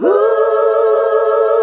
HUH SET A.wav